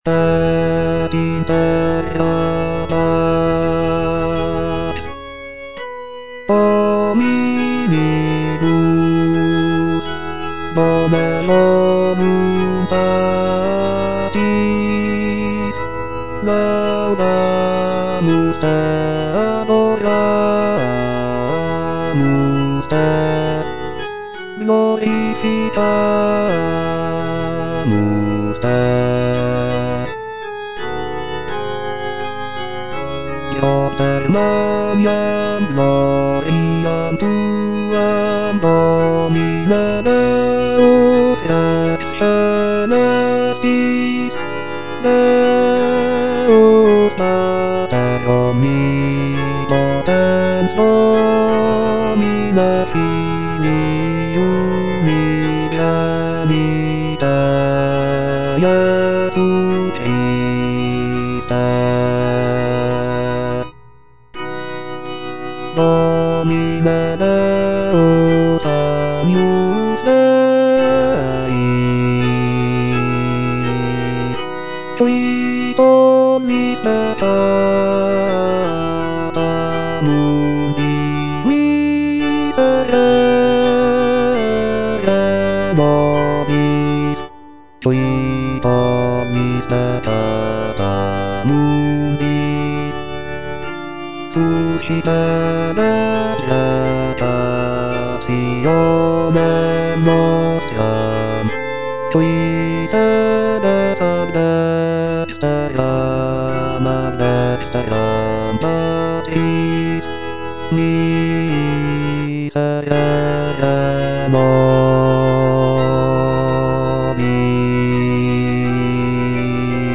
Completed in 1887, it is a mass setting for mixed choir, soloists, and orchestra.
Rheinberger's composition is characterized by rich harmonies, lyrical melodies, and a blend of traditional and innovative elements.
J.G. RHEINBERGER - MISSA MISERICORDIAS DOMINI OP.192 Gloria - Bass (Voice with metronome) Ads stop: auto-stop